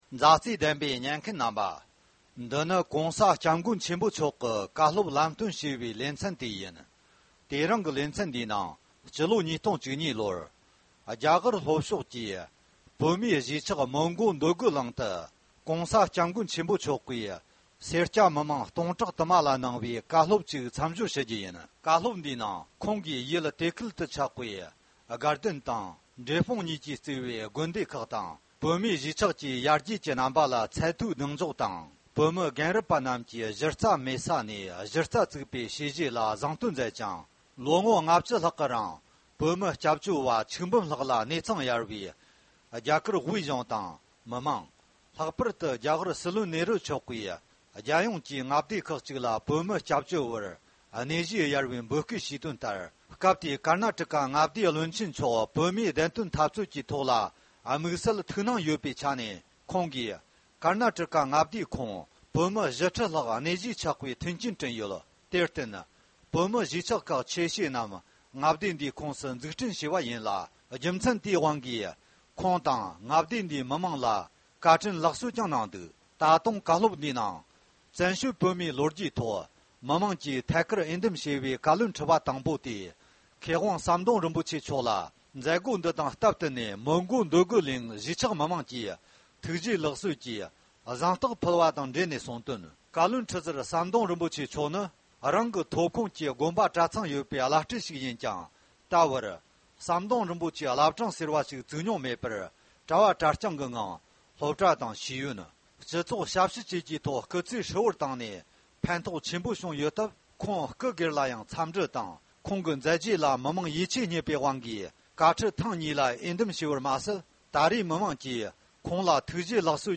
༸གོང་ས་༸སྐྱབས་མགོན་ཆེན་པོ་མཆོག་ནས་སྤྱི་ལོ་༢༠༡༢ལོར་མོན་གྷོ་འདོད་རྒུ་གླིང་བོད་མི་རྣམས་ལ་བཀའ་སློབ་ལམ་སྟོན་གནང་བ།